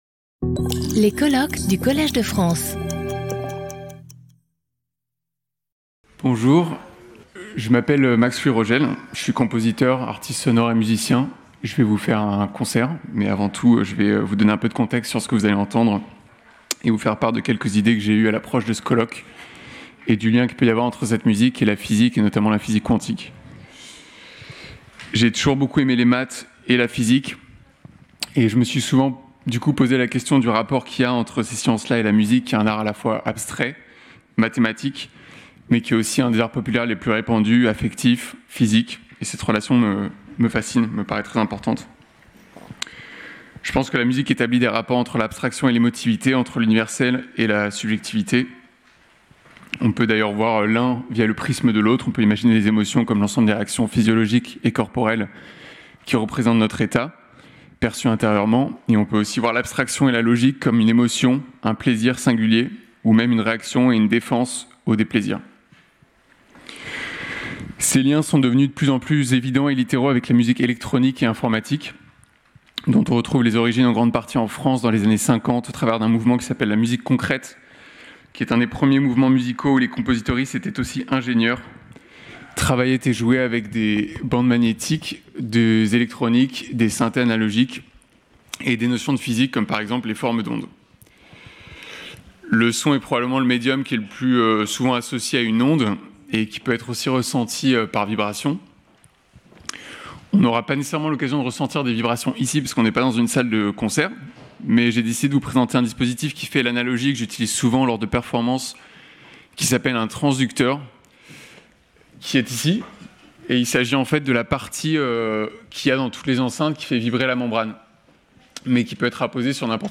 Musical interlude
Symposium